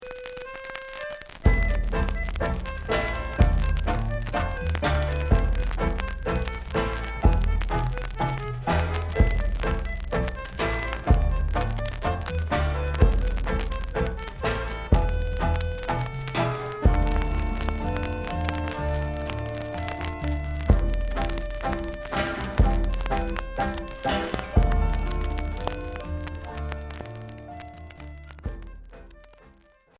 Folk Rock Cinquième 45t retour à l'accueil